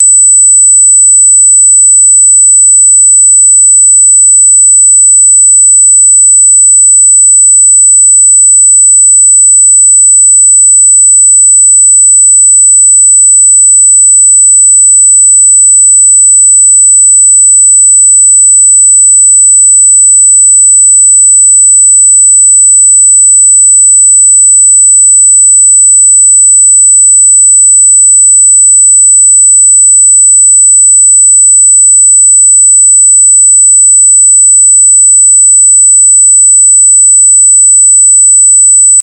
高周波で鋭い音として知られています。
このモスキート音は、モスキートの鳴き声に似た特徴的な音であり、聞く者にとって耳障りな体験となる可能性があります。